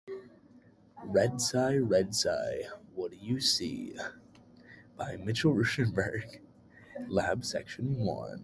A repetitive, rhythmic chant follows Red Cy as it spots different colored campus sights, ending with the Cyclones seeing them all looking back.